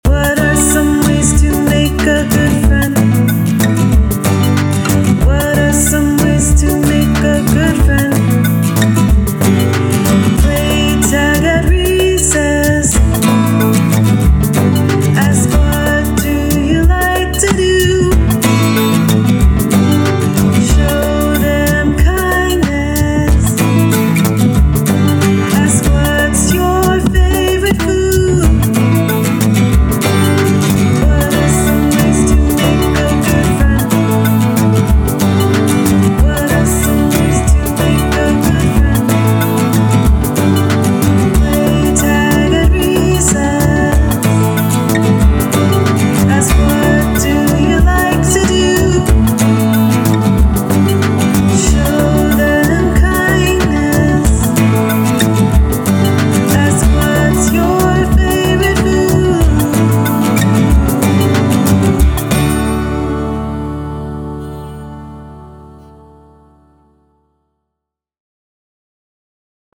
Make a Good Friend - Guit and Voice Mix 1
Written by Lawton Elementary School Students